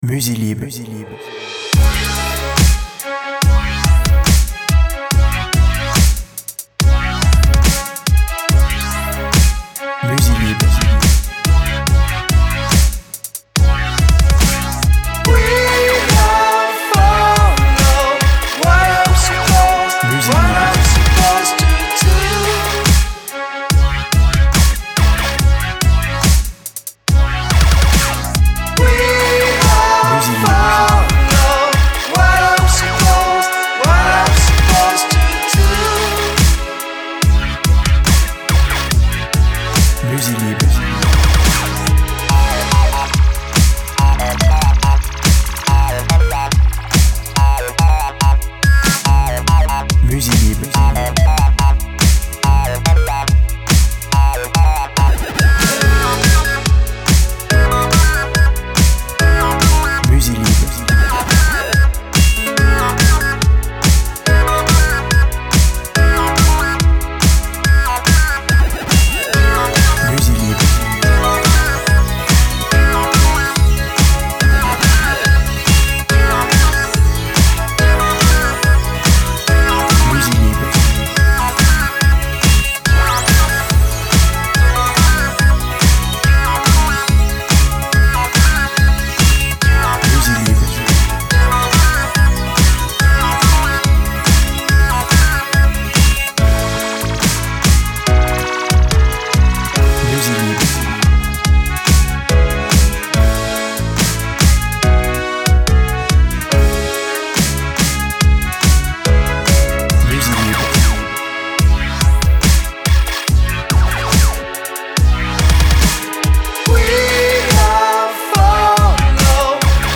BPM Lent